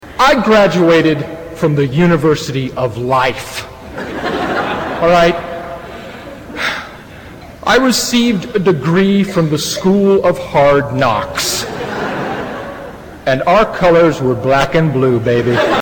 Tags: Class of 2003 Will Ferrell Harvard commencement speech audio clip